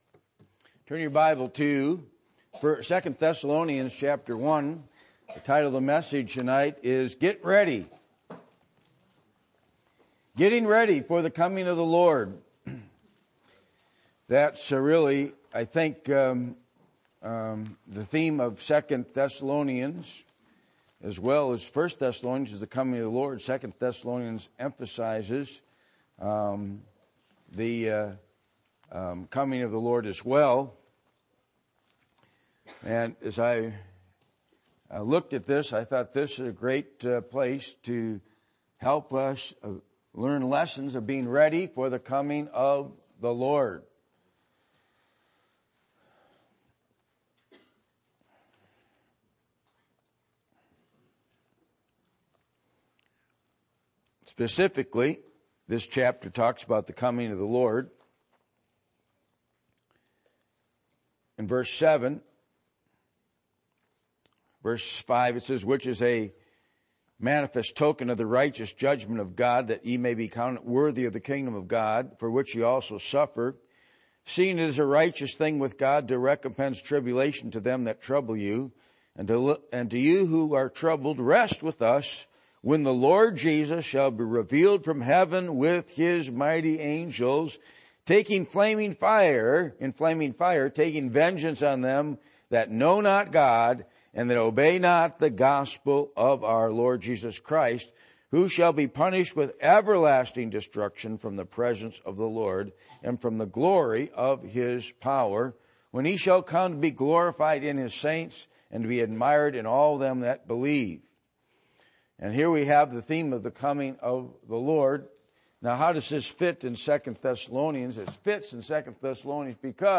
2 Thessalonians 1:4-9 Service Type: Sunday Evening %todo_render% « What Have You been Given